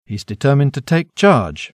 INTONAZIONI & ACCENTI DI PAROLA
Discesa e salita di livello